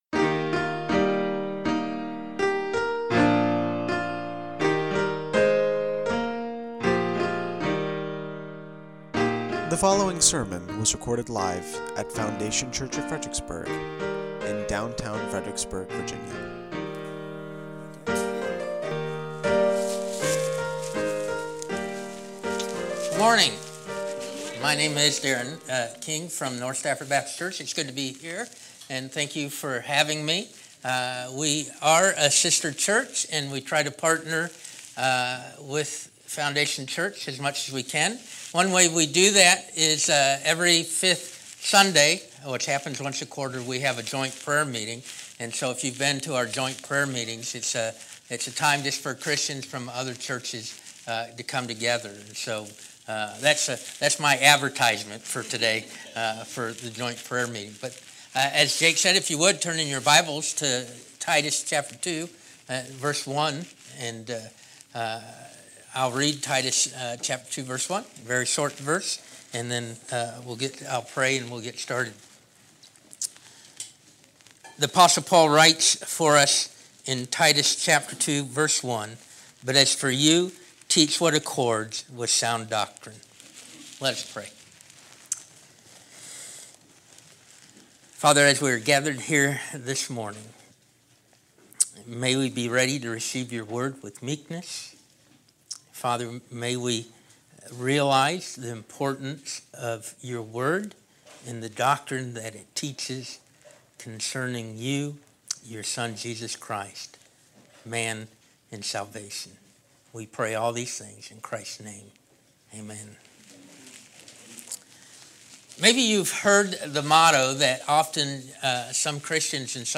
Lord’s Day Gathering – May 19, 2024 | Foundation Church Fredericksburg